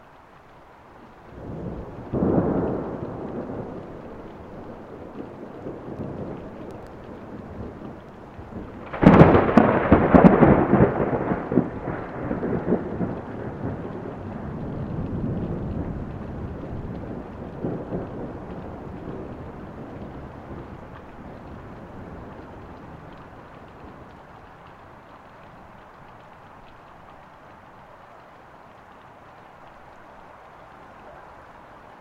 雷霆风暴" 相当接近的雷声响起
描述：单一的雷声和雨声，略有失真，刚刚超过峰值水平。遗憾的是，你可以听到由螺栓本身产生的辐射波噼啪声，然后你听到几秒钟后的音频。 用高质量的麦克风直接录制到电脑上。
Tag: 场记录 闪电 性质 风暴 雷风暴 天气